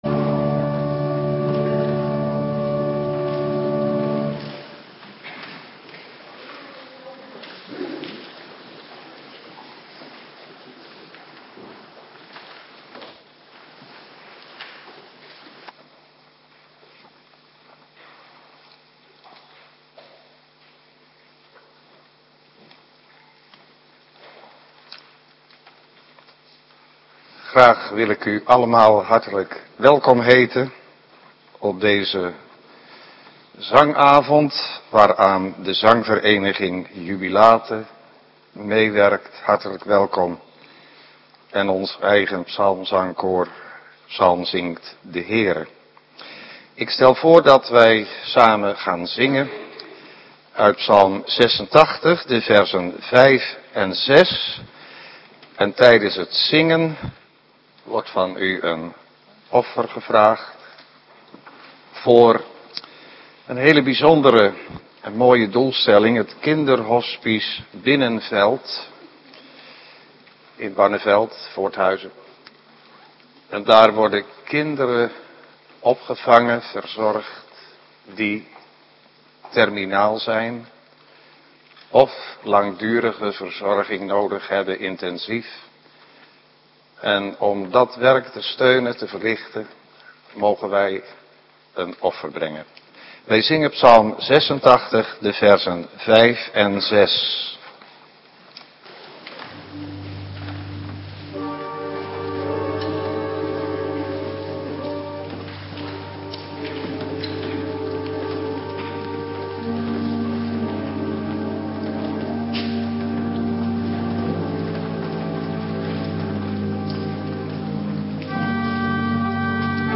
Psalmzangkoor ?Psalmzingt den Heere? Gastkoor ?Jubilate?
Muzikale intermezzo
dwarsfluit
orgel